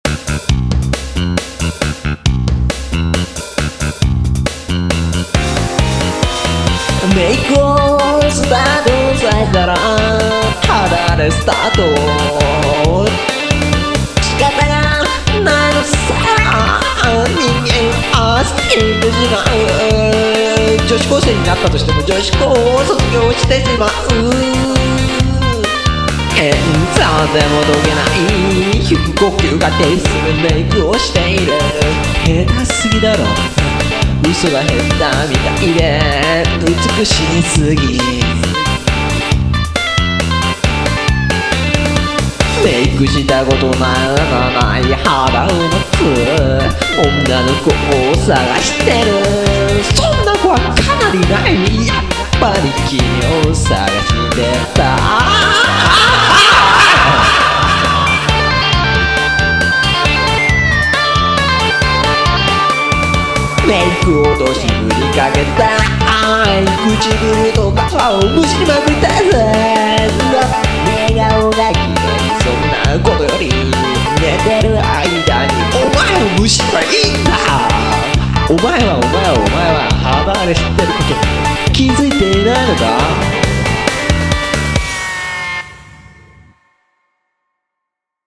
leo_make.mp3 My first remix...